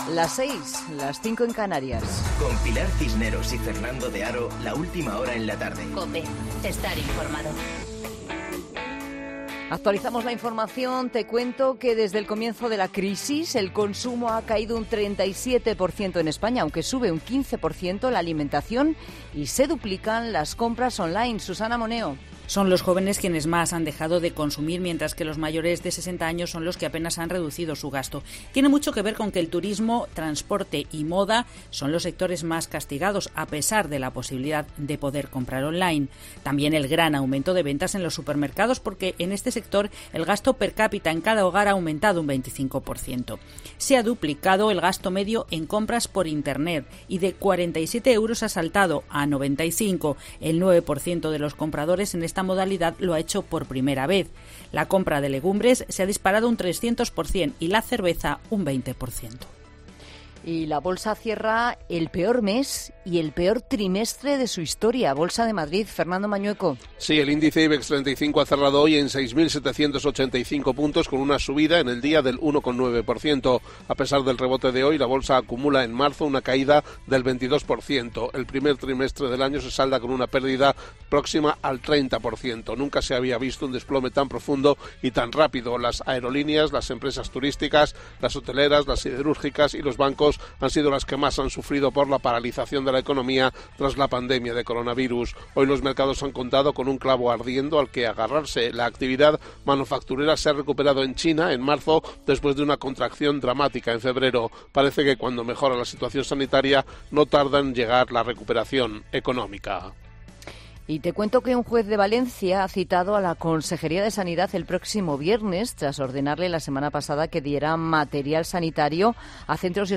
Boletín de noticias de COPE del 31 de marzo de 2020 a las 18.00 horas